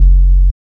3708R BASS.wav